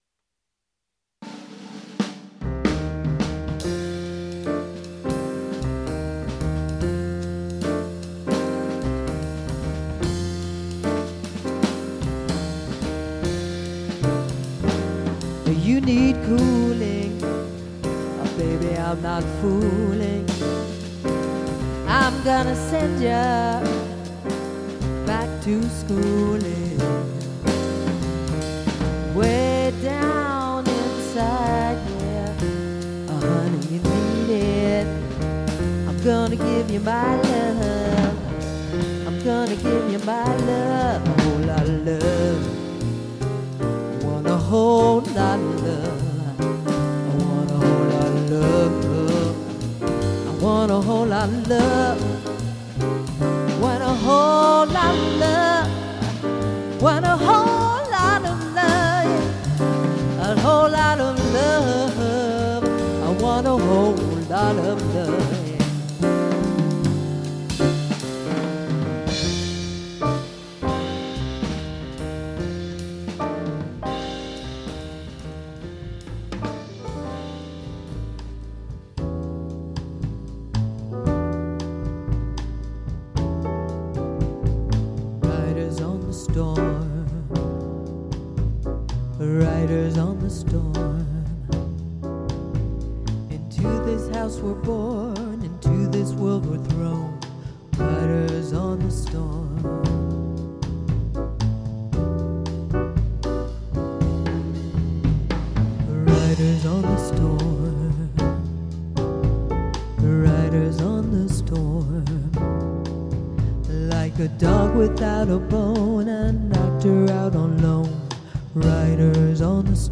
Des notes de Jazz, de grands classiques revisités.